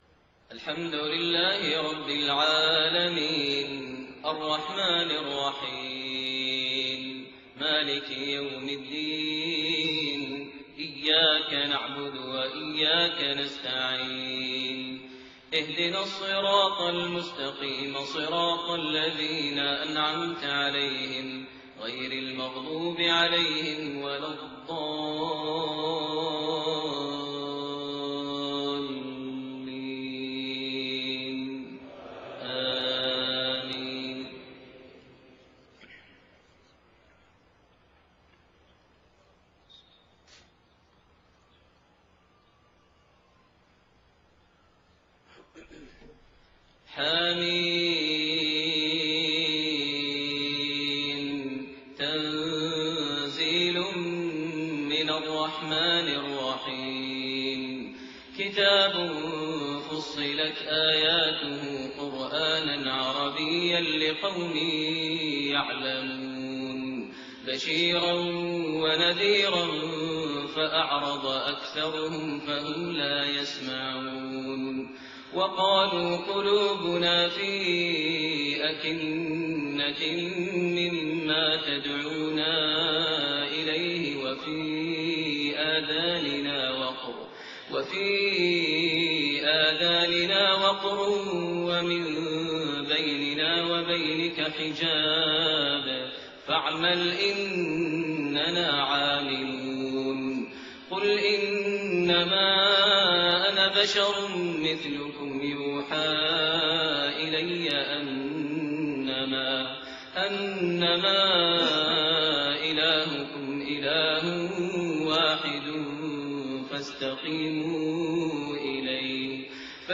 صلاة الفجر 3-5-1429 من سورة فصلت1-24 > 1429 هـ > الفروض - تلاوات ماهر المعيقلي